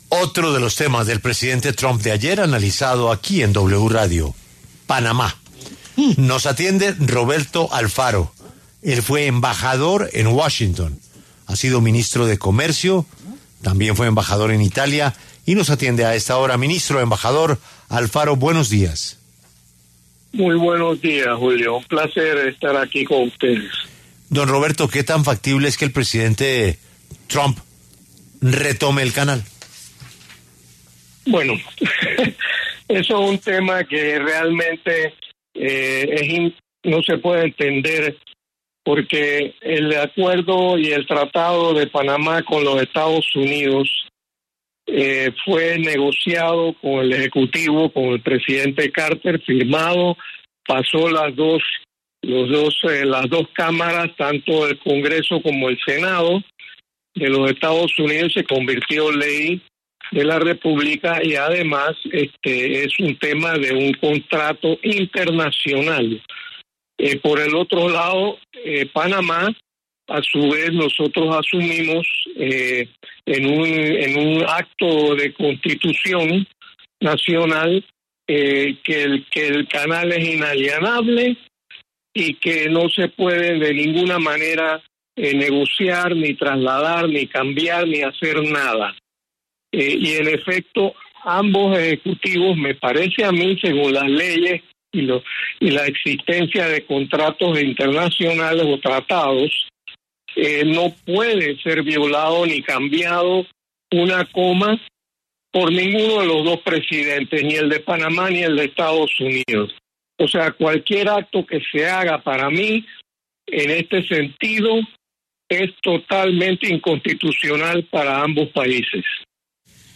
Roberto Alfaro, exembajador de Panamá en Estados Unidos, pasó por los micrófonos de La W para hablar sobre la posesión de Donald Trump y las decisiones que dio a conocer en su primer día como presidente.